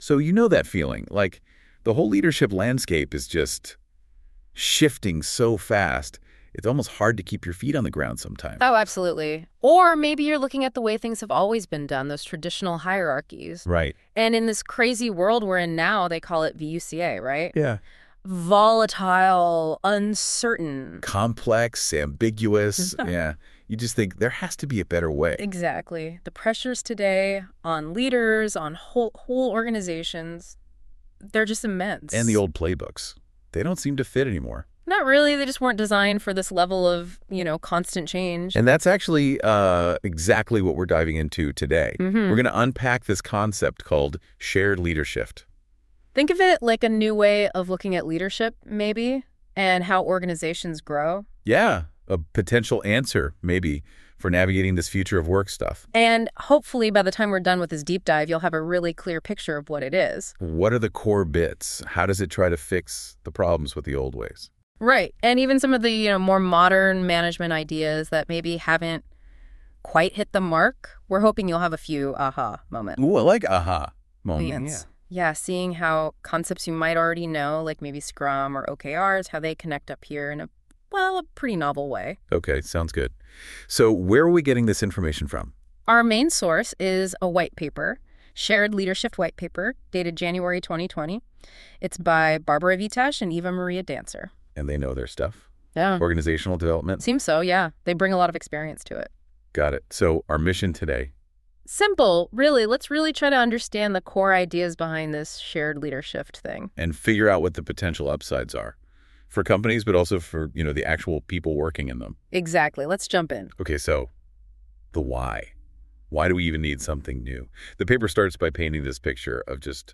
Presented in an interactive AI dialogue with NotebookLM – professionally told, in English.